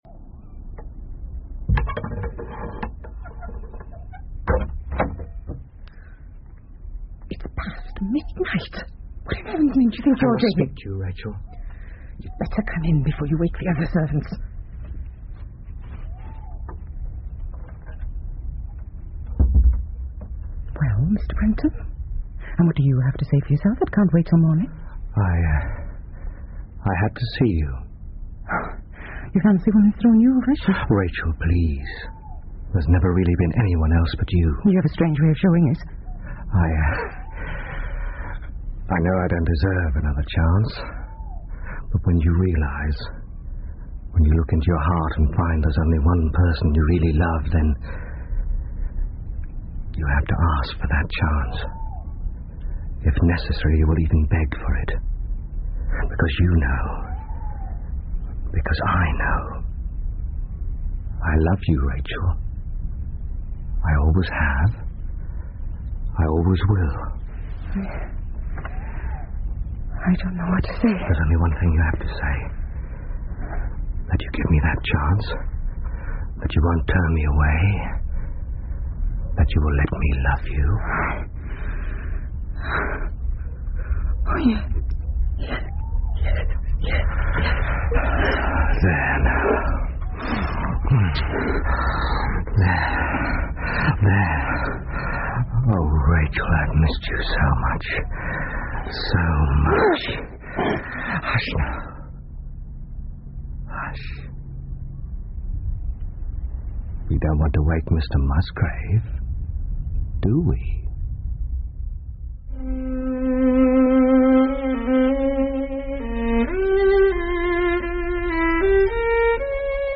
福尔摩斯广播剧 The Musgrave Ritual 1 听力文件下载—在线英语听力室